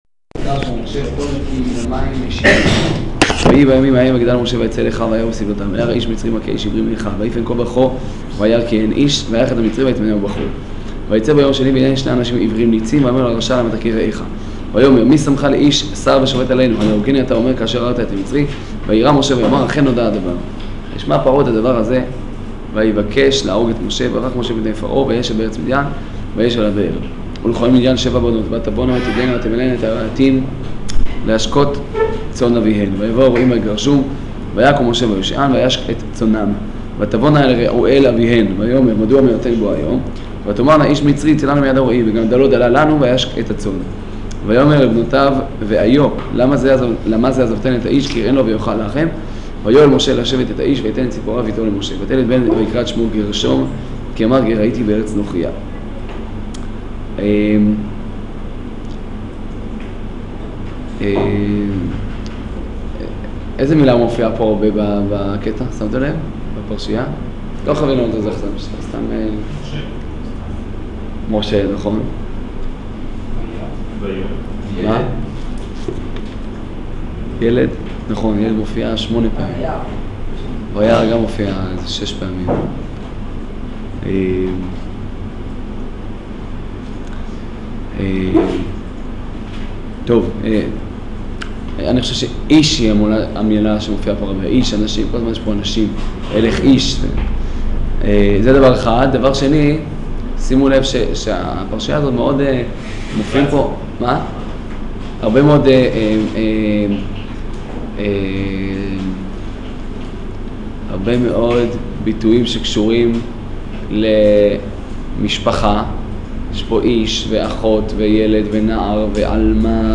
שיעור פרשת שמות